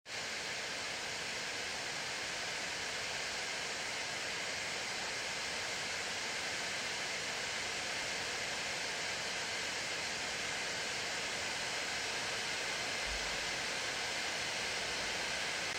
Die Leistung des M5 Max im MacBook Pro 16“ es im Leistungs-Modus hingegen nicht, aber das Kühlsystem dreht sehr konstant und es ist im Ende „nur“ Luftrauschen zu hören. Auch dauert es gut 40 Sekunden, bevor die Lüfter überhaupt anfangen hoch zu drehen, kurze Lastspitzen bringen auch das neue MacBook Pro mit M5 Max nicht aus der Ruhe.
MacBook Pro 16“ M5 Max: Kühlsystem unter Last (aus nächster Nähe!)